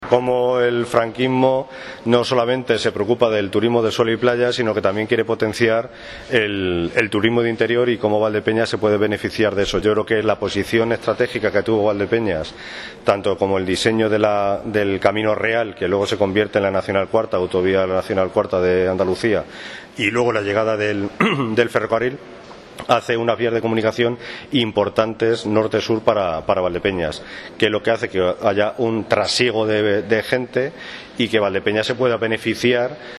Antes de dar comienzo la ponencia, a la que asistieron decenas de personas, muchos de ellos estudiantes de secundaria y bachillerato, el Teniente de alcalde de Cultura, Turismo, Comercio y Educación, Manuel López Rodríguez, que señaló que “el franquismo no solo se preocupó del turismo del sol y playa, sino que también potenció el turismo de interior, pudiéndose Valdepeñas beneficiar de eso”.